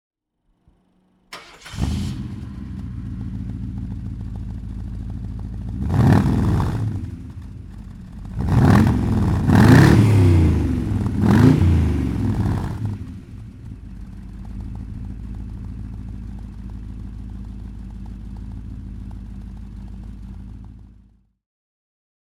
Motorsounds und Tonaufnahmen zu De Tomaso Fahrzeugen (zufällige Auswahl)
De Tomaso Deauville (1976) - Starten und Leerlauf
De_Tomaso_Deauville_1976.mp3